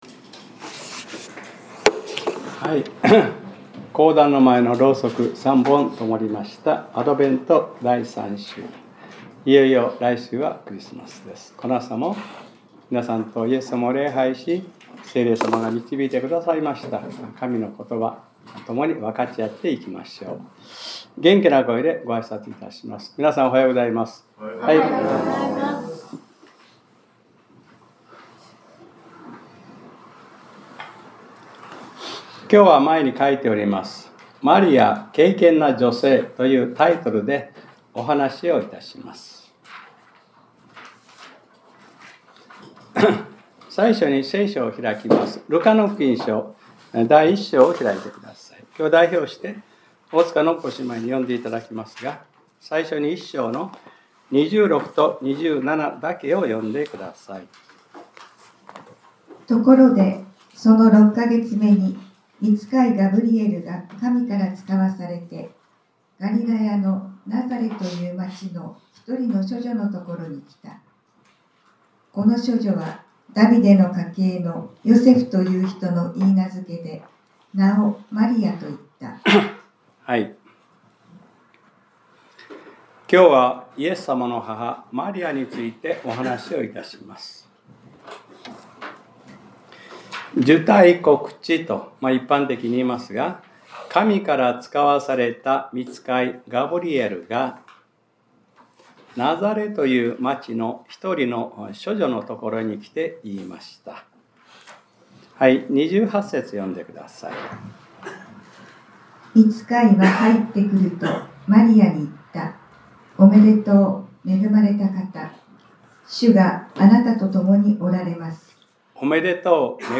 2025年12月14日（日）礼拝説教『 マリヤｰ敬虔な女性 』 | クライストチャーチ久留米教会